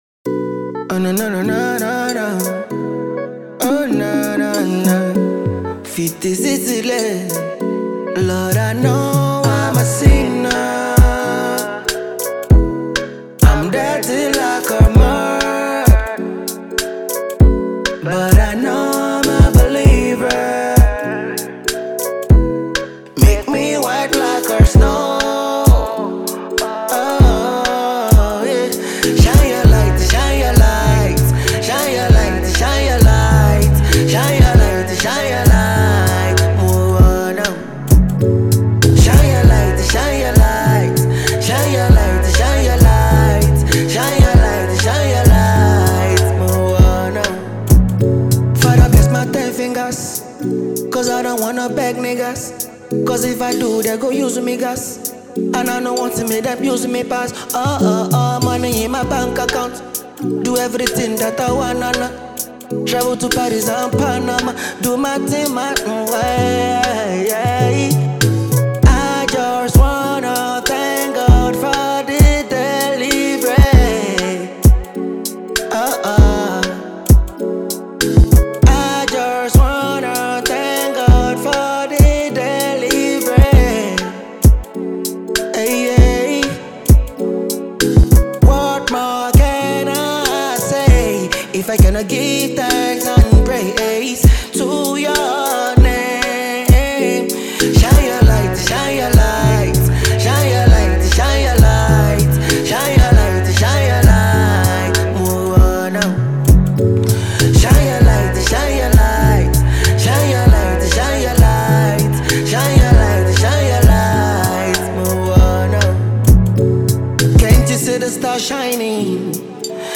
Afro-pop singer
is an inspirational number